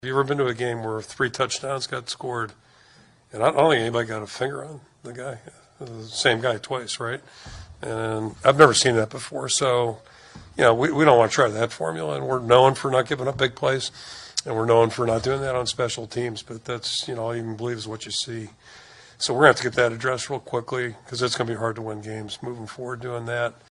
That’s Iowa coach Kirk Ferentz who says there are a variety of factors that went into it.